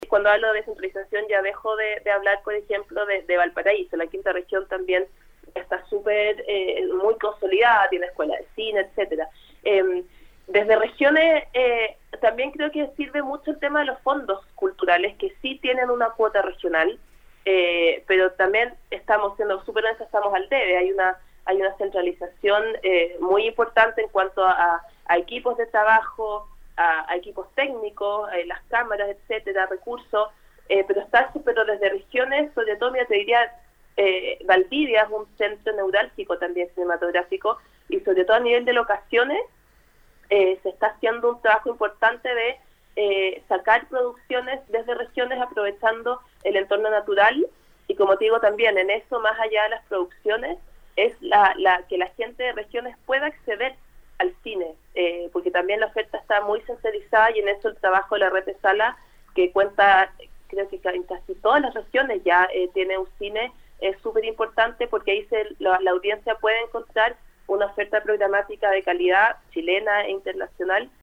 En entrevista con Nuestra Pauta